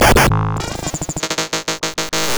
Glitch FX 29.wav